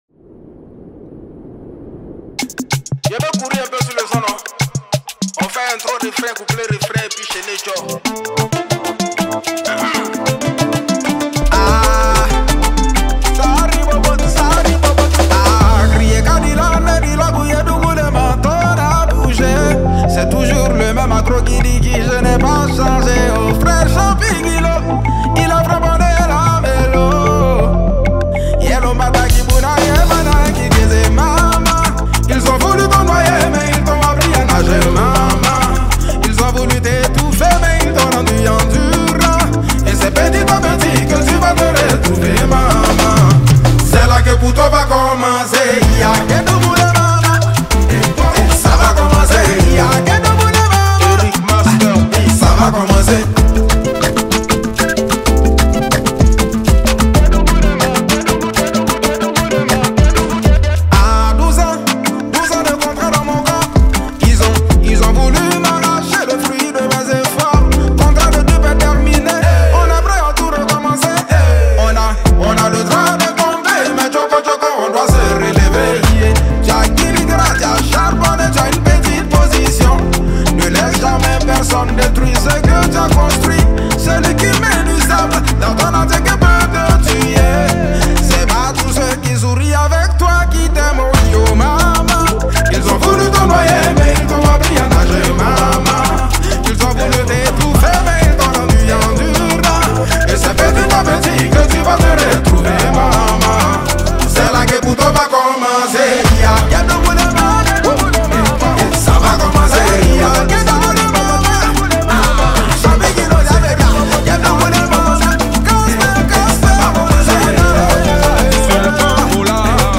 | Coupé décalé